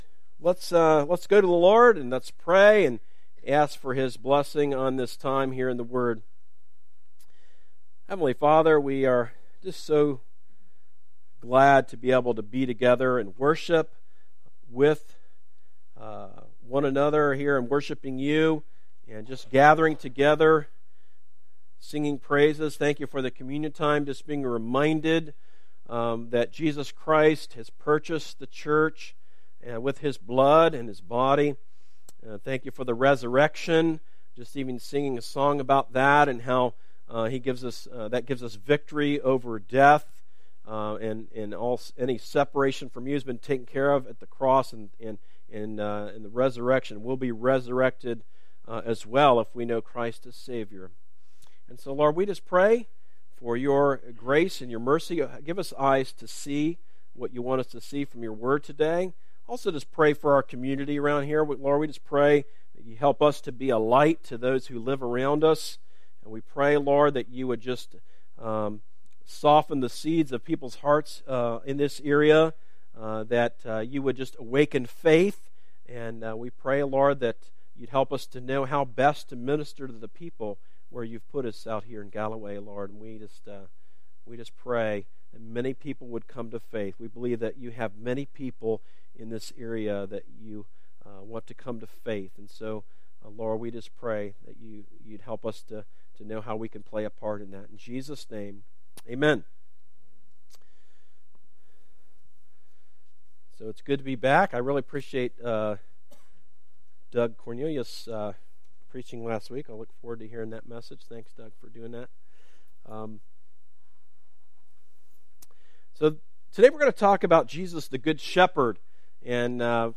A message from the series "Stand Alone Sermons."
Sermons that are not part of a series